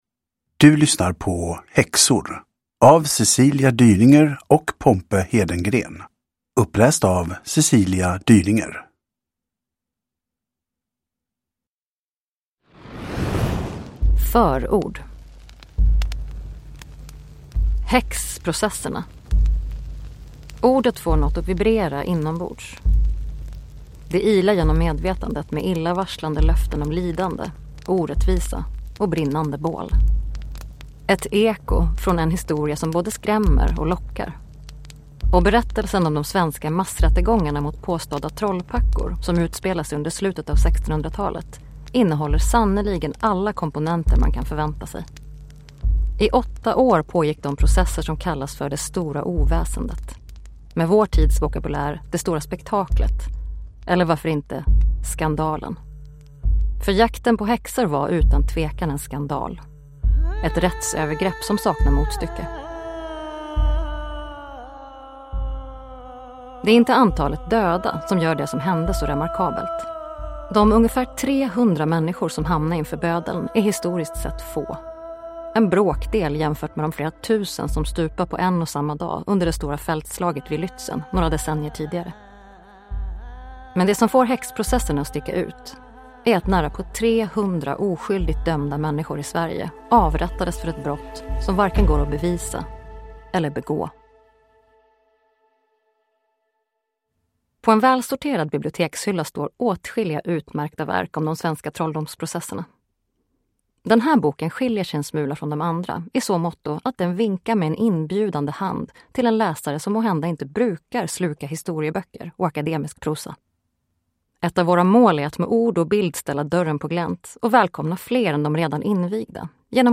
Häxor : när Satans raseri svepte över Sverige – Ljudbok